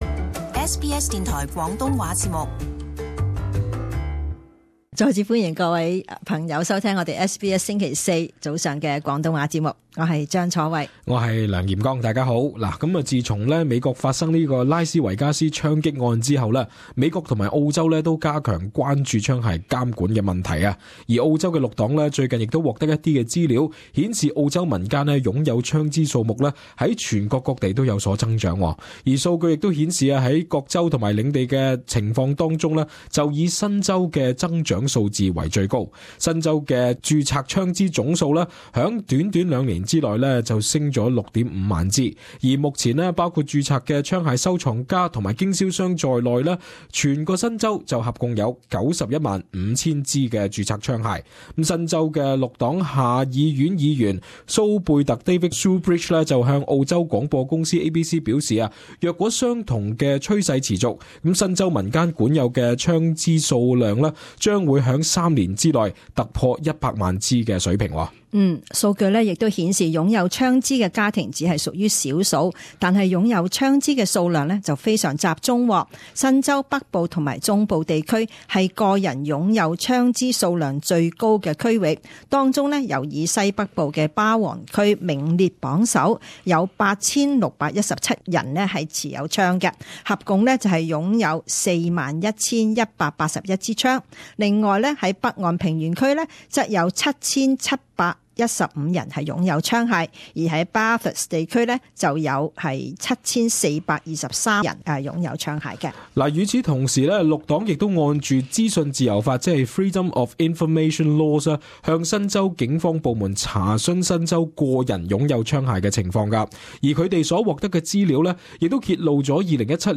【時事報導】新州居民獨擁槍300支